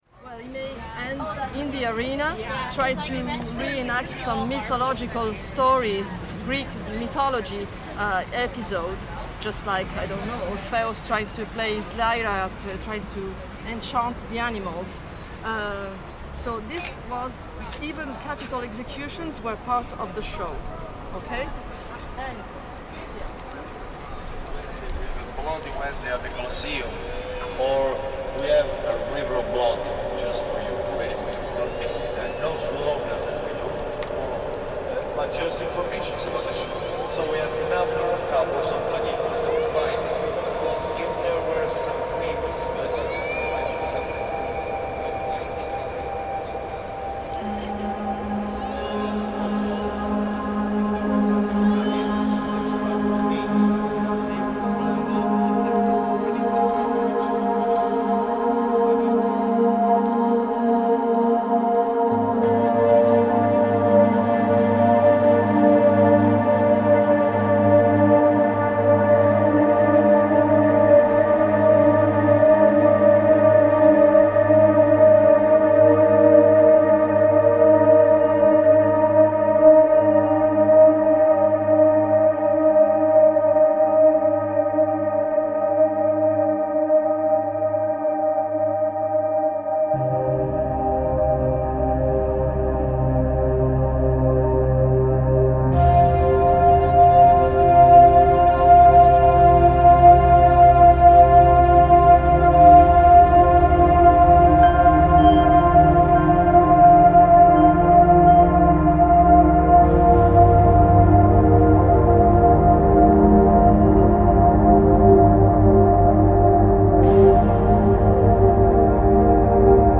The field recording takes us from tour group to tour group inside the world-famous Colosseum, with each guide bringing their own style and their own facts and anecdotal stories about the incredible history of this space.
This composition imagines standing at the back of one of these tour groups, half-listening to what the guide is saying, but then allowing the eyes to drift upwards, allowing the attention to drift outwards, and a pure sensation of the beauty, importance and history of this space to be felt directly throughout the body. The piece is about direct, unmediated experience of an iconic, important site, without the need for further explanation, for tour guides, or for anything else, and how a simple and pure connection between the individual and the space is more challenging than ever in today's age of overtourism.